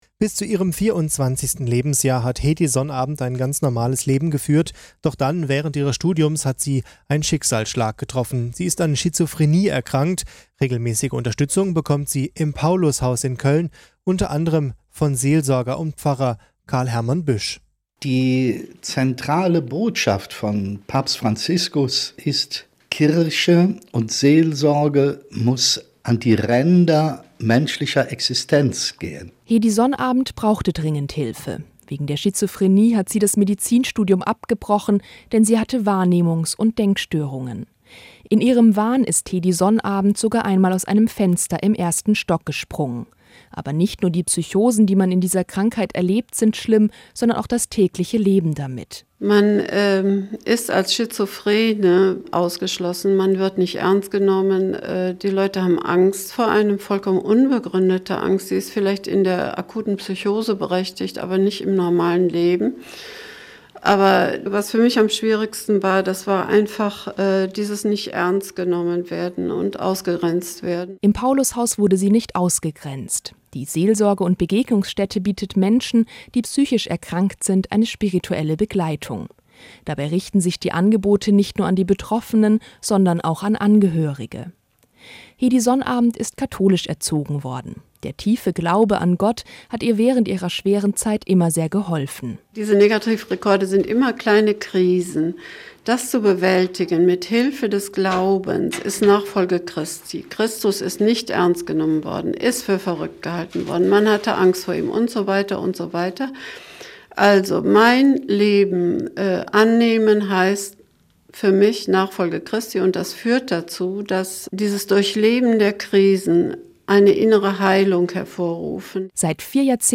Sendung zum Nachhören